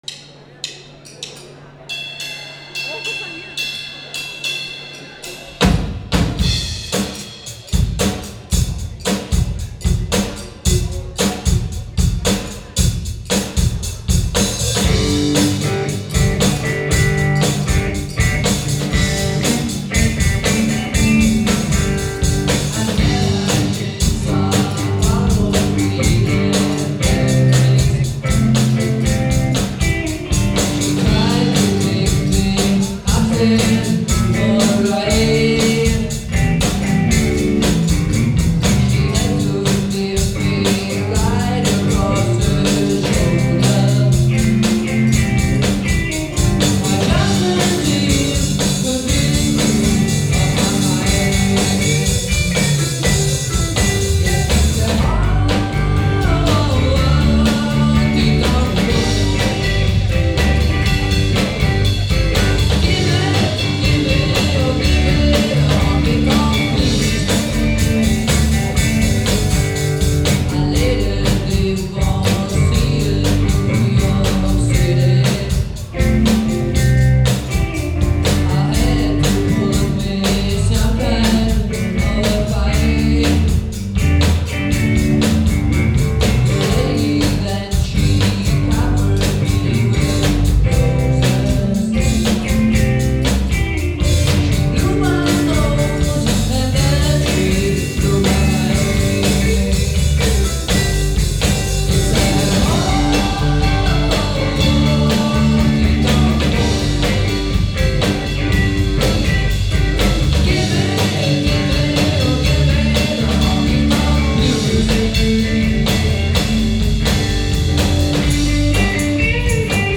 • Blues
• Rockband
• Coverband